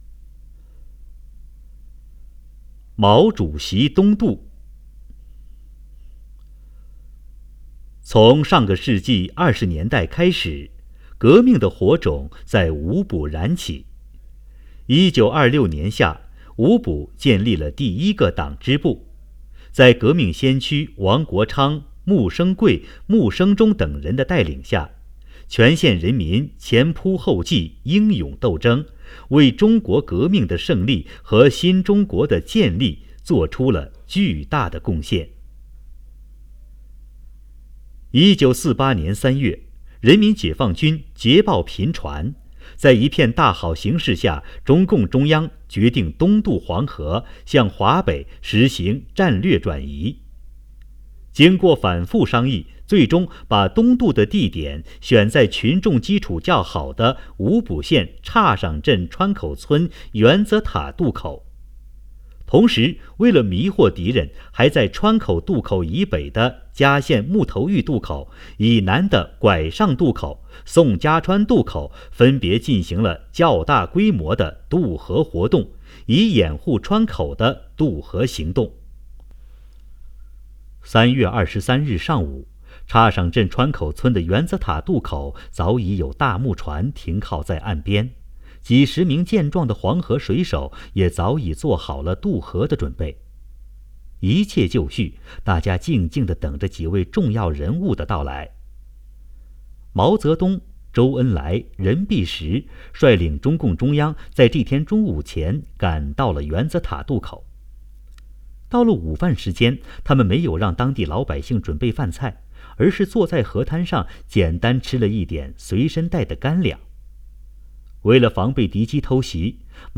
【红色档案诵读展播】毛主席东渡